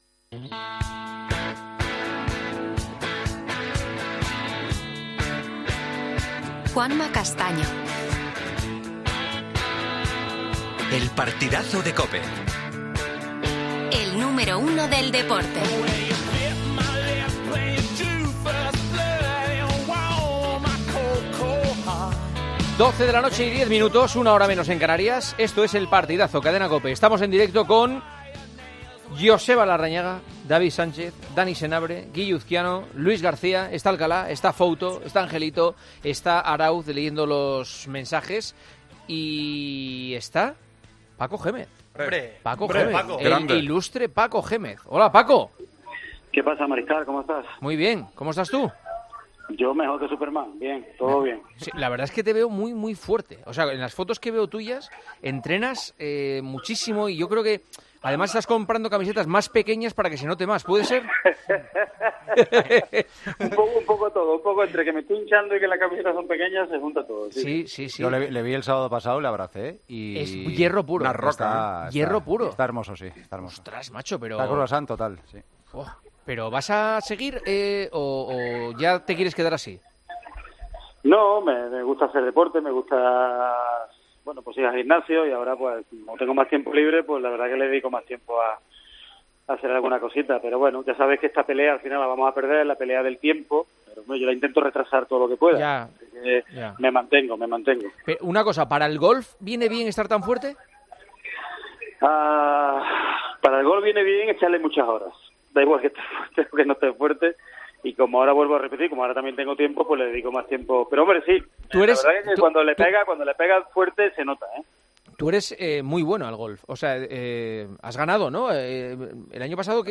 AUDIO: El entrenador, que compartió vestuario con el seleccionador, ha hablado en El Partidazo de COPE sobre la situación que atraviesa Luis Enrique...